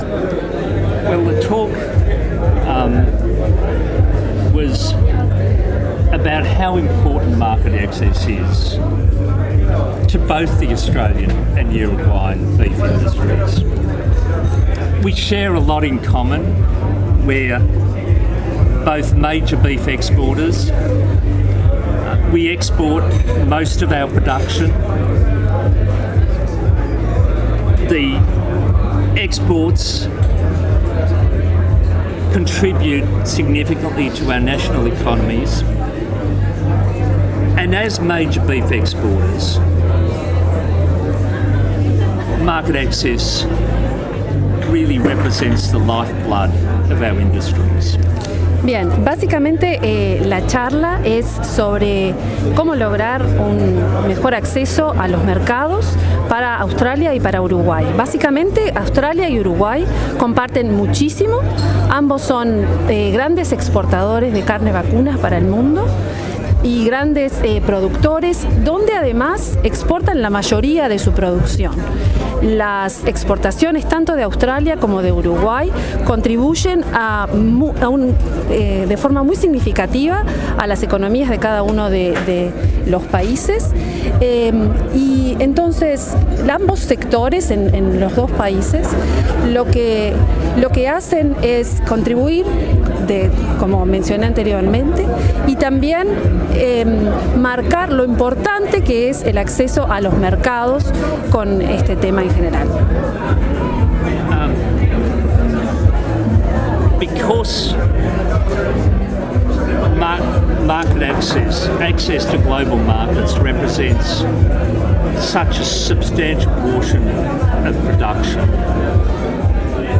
AUDIO con traducción. mp3.